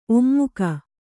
♪ ommuka